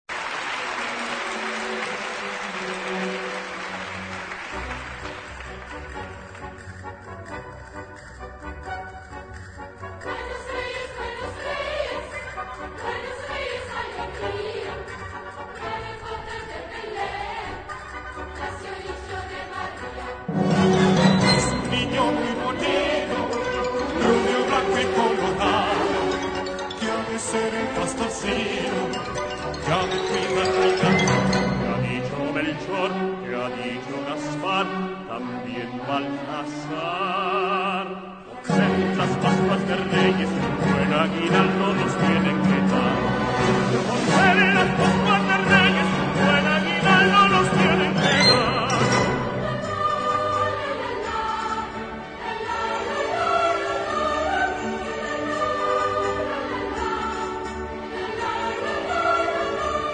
music: Traditional
key: G-mayor